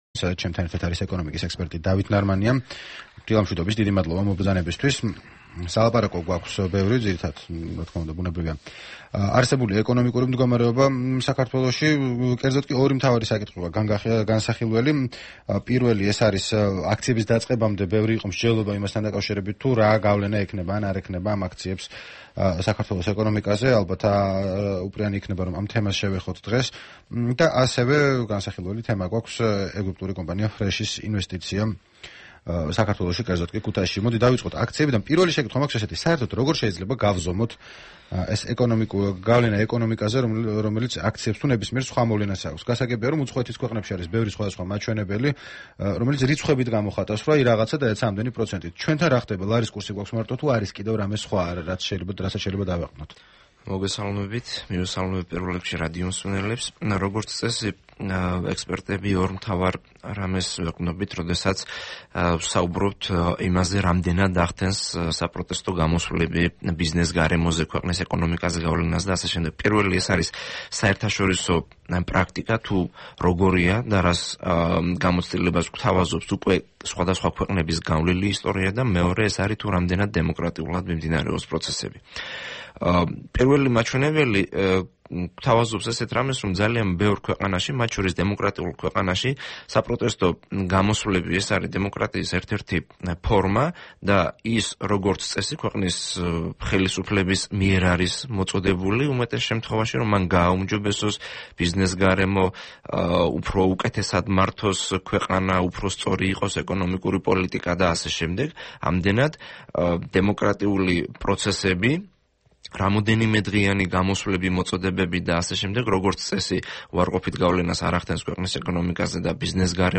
ოთხშაბათს რადიო თავისუფლების დილის პროგრამის სტუმარი იყო ეკონომიკის ექსპერტი დავით ნარმანია.